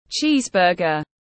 Bánh mì kẹp phô mai tiếng anh gọi là cheeseburger, phiên âm tiếng anh đọc là /ˈtʃiːzˌbɜː.ɡər/
Cheeseburger /ˈtʃiːzˌbɜː.ɡər/